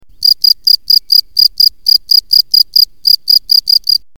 Field cricket - Germany